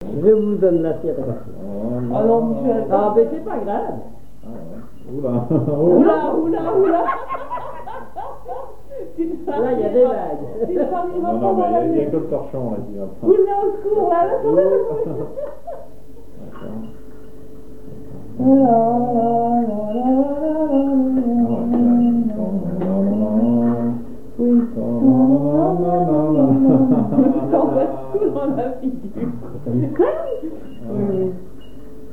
Conversation autour des chansons et interprétation
Catégorie Témoignage